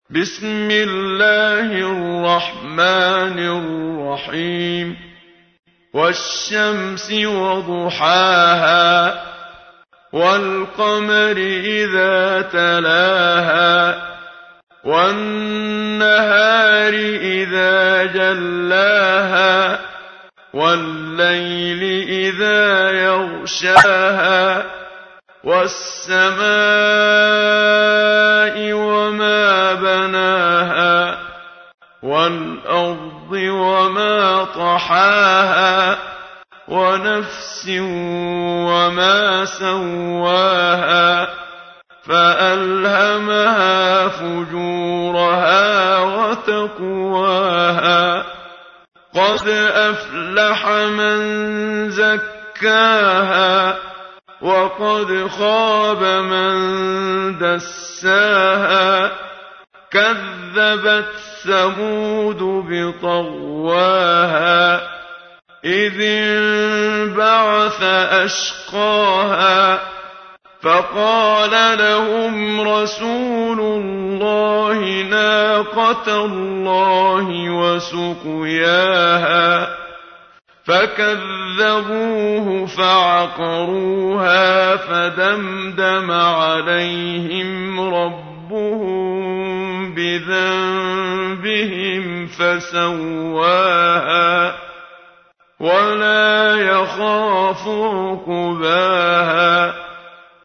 تحميل : 91. سورة الشمس / القارئ محمد صديق المنشاوي / القرآن الكريم / موقع يا حسين